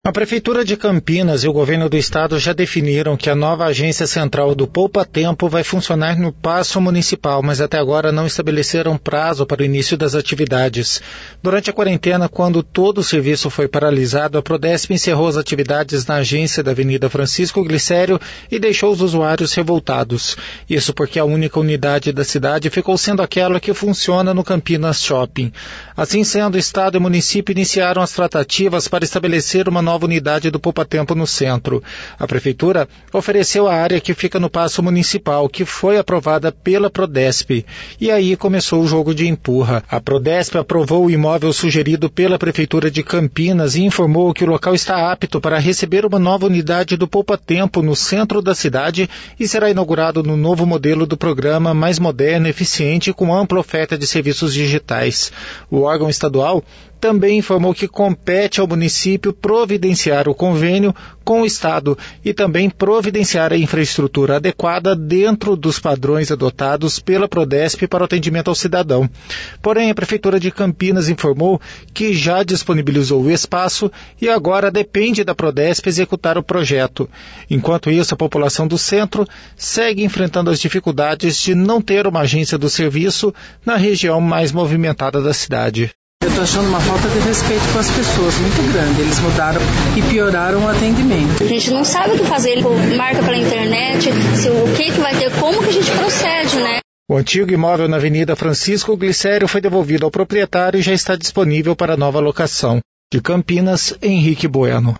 Eles mudaram e pioraram o atendimento”, disse uma usuária do serviço.